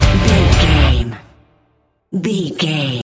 Aeolian/Minor
drums
electric guitar
bass guitar
violin